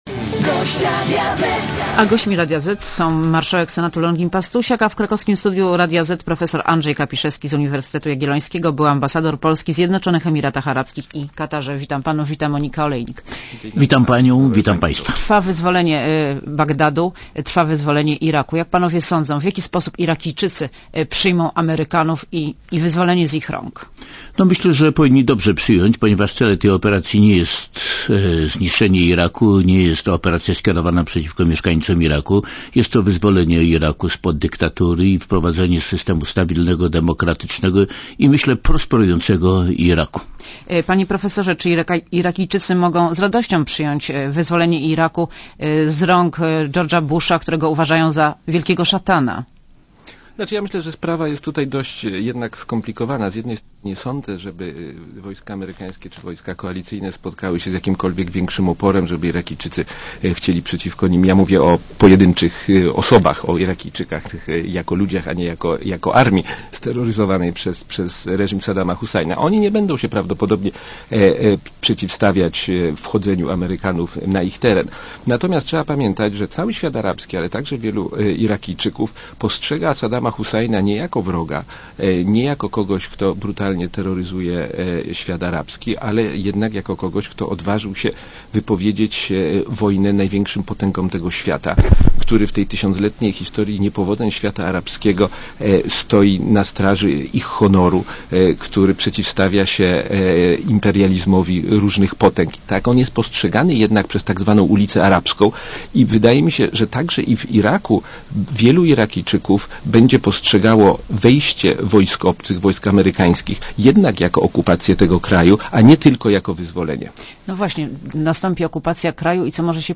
Monika Olejnik rozmawia z marszałkiem Senatu Longinem Pastusiakiem i profesorem Andrzejem Kapiszewskim byłym ambasadorem Polski w Zjednoczonych Emiratach Arabskich